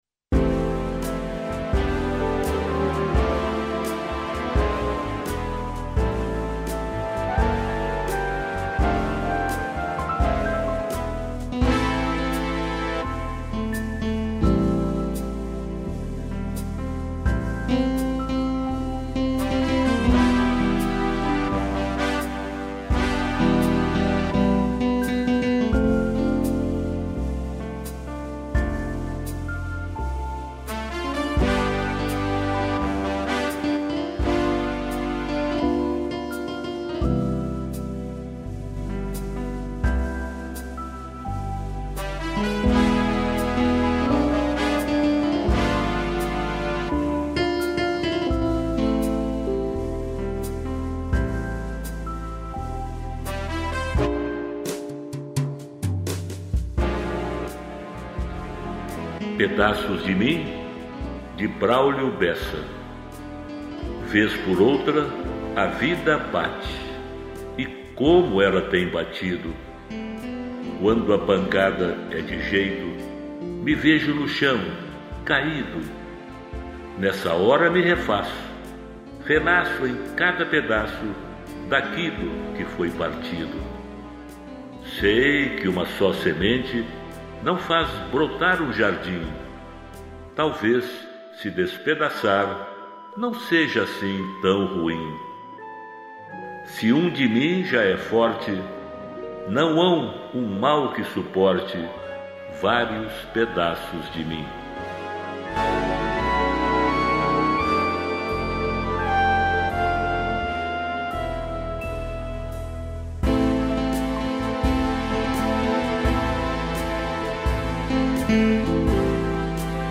Poemas de vários poetas interpretados